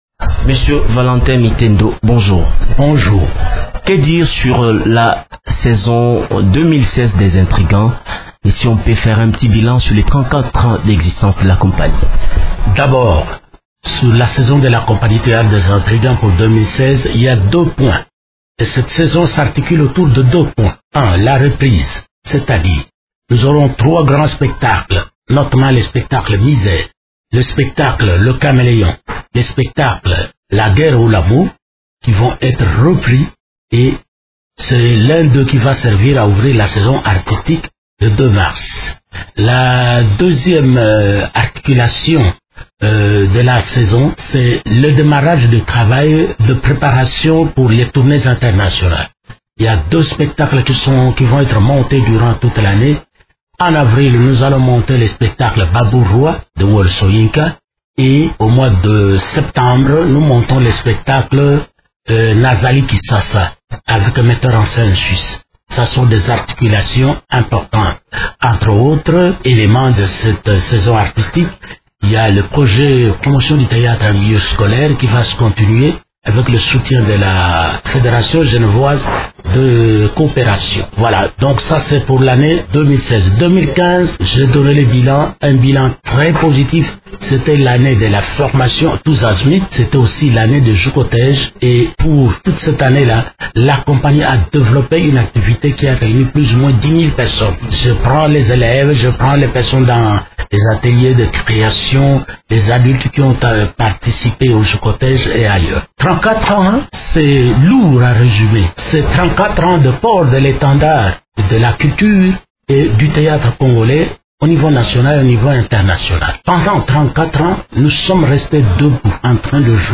parle de cette nouvelle saison artistique avec